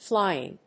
音節fly・ing 発音記号・読み方
/flάɪɪŋ(米国英語)/